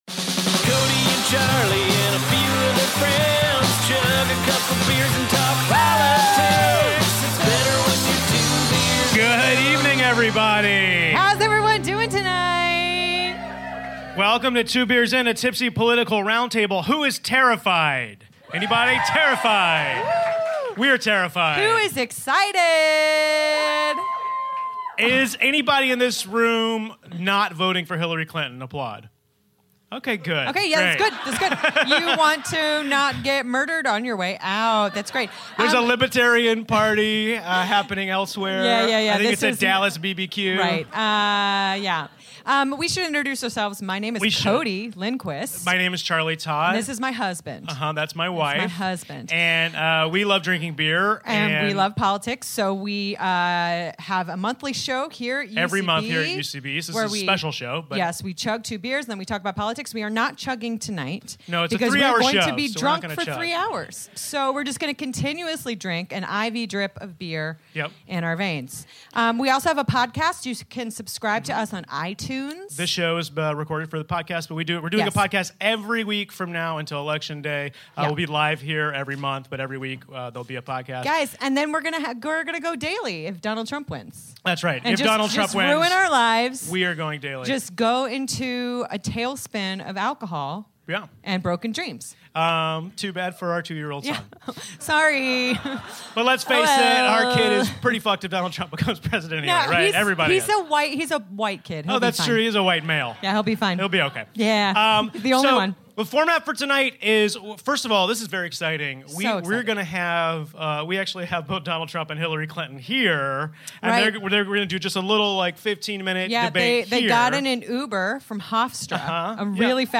Special episode recorded live from the Upright Citizens Brigade Theatre the night of the first debate.